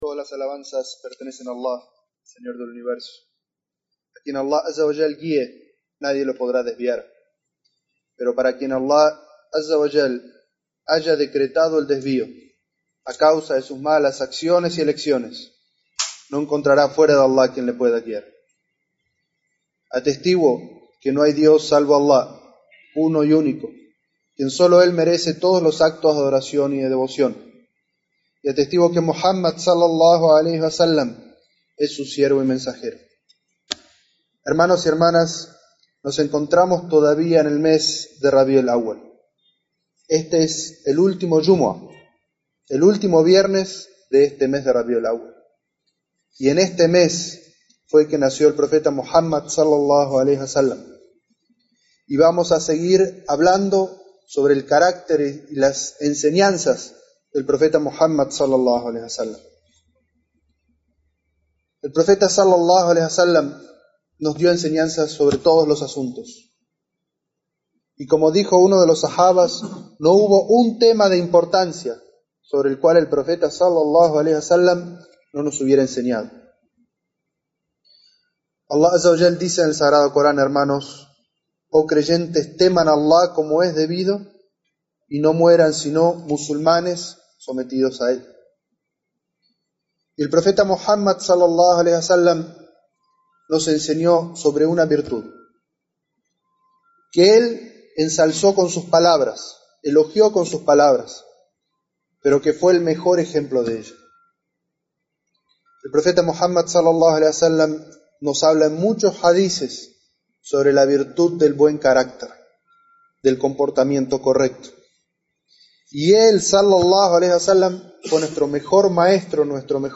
Jutbah: El buen carácter del Profeta Muhammad, que la paz y las bendiciones de Allah sean con él